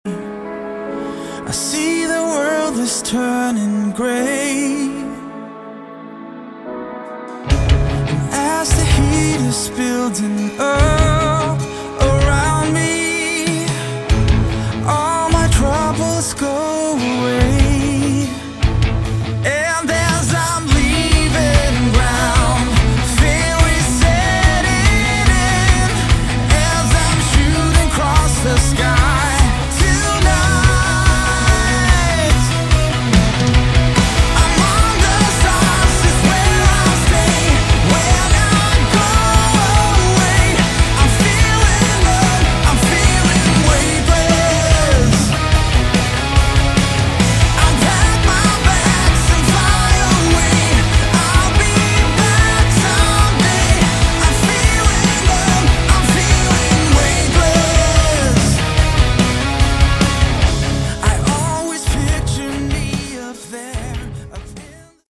Category: AOR / Melodic Rock
lead vocals
lead guitar
rhythm guitar
keyboards
bass
drums